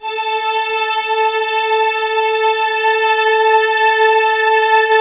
Index of /90_sSampleCDs/Optical Media International - Sonic Images Library/SI1_Lush Strings/SI1_Lush Detune